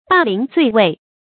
霸陵醉尉 bà líng zuì wèi 成语解释 形容失官之后受人侵辱。